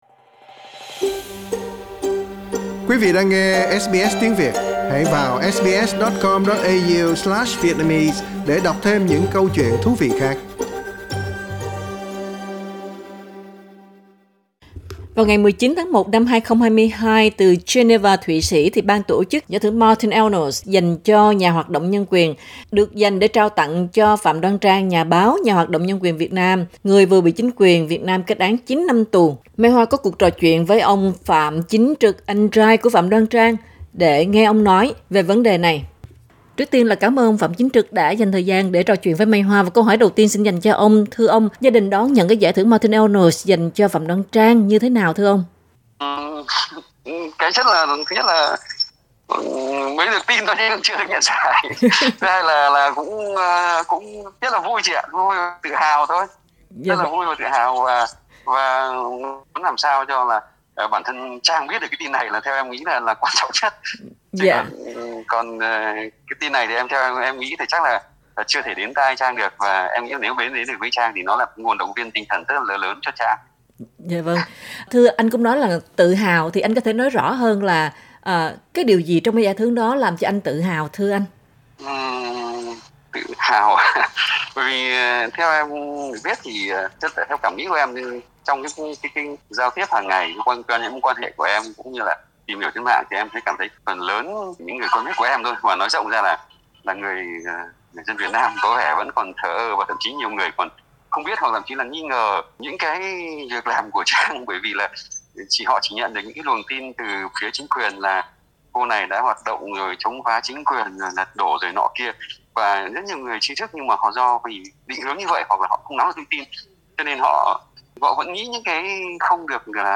Lần đầu tiên trả lời phỏng vấn SBS